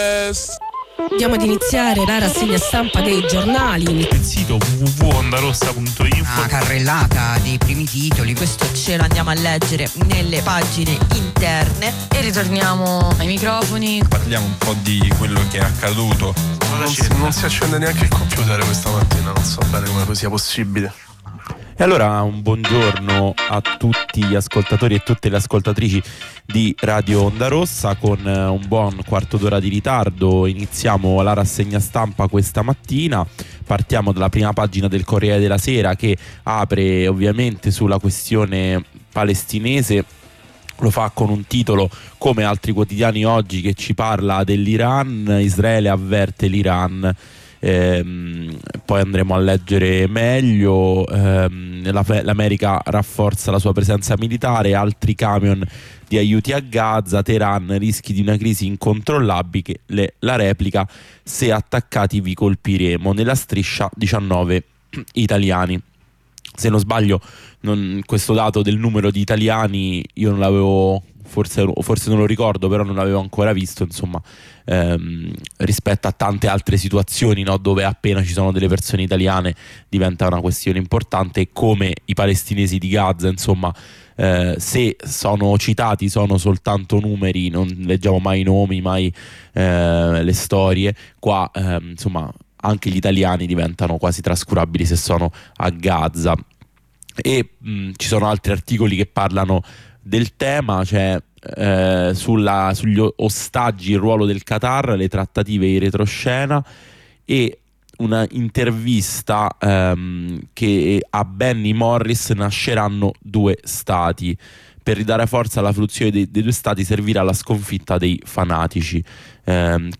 Tutti i giorni alle 8 lettura e commento dei quotidiani. Oggi si parla di: Palestina e medioriente, sanità, meeting di FdI e patrimoniale.